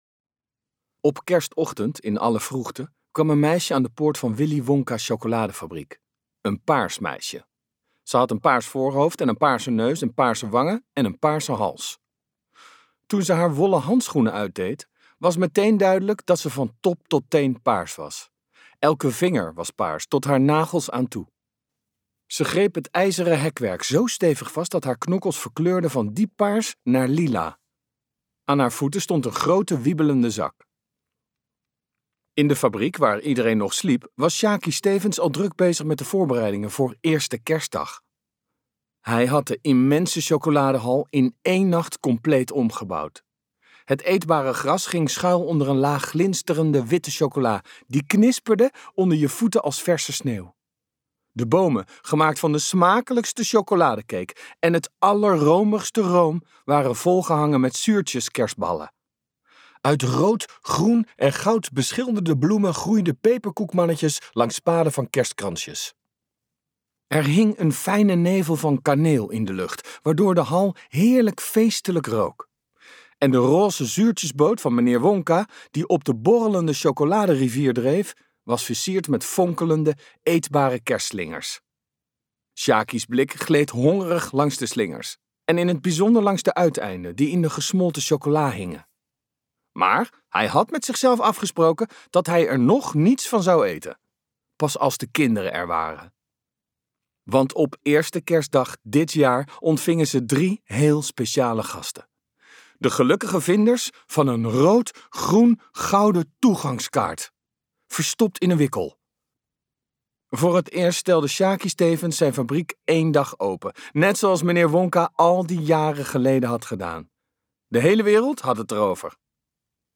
Uitgeverij De Fontein | Sjakie en de kerstfabriek en andere verhalen luisterboek